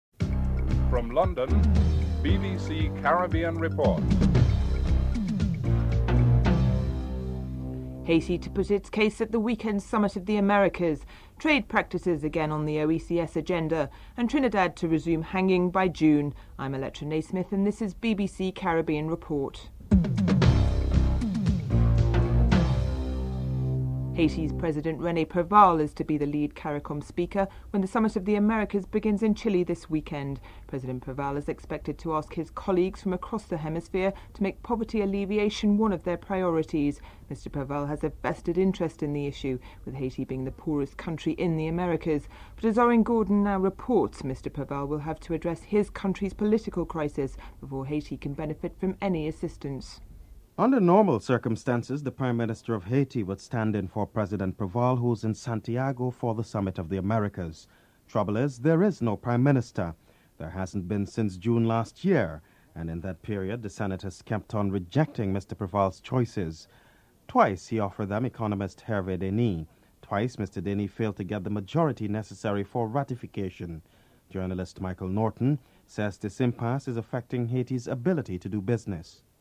9. Recap of top stories (14:56-15:15)